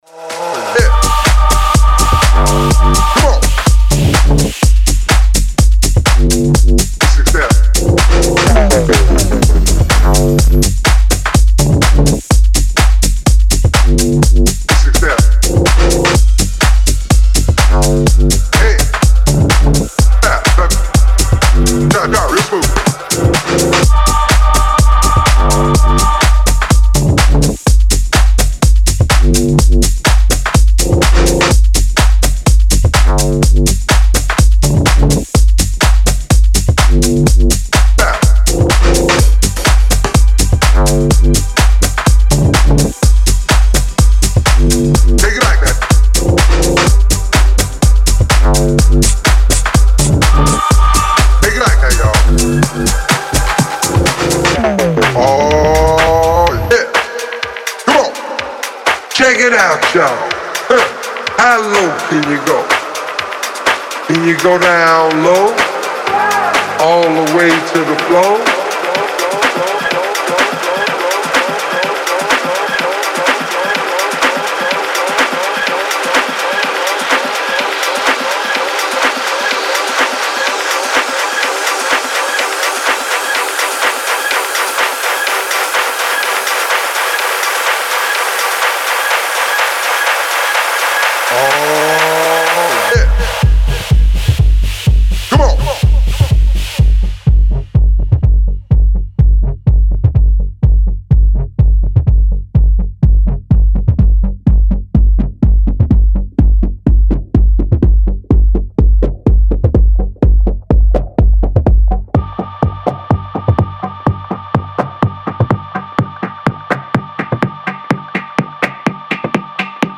Style: Tech House / House